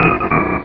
Cri d'Okéoké dans Pokémon Rubis et Saphir.